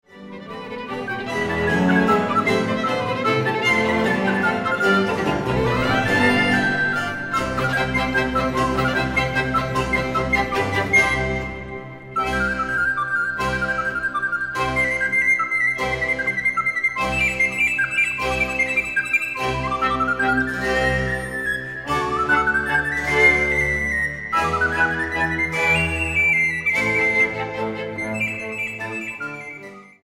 flauta de pico.
para flauta sopranino, cuerdas y contínuo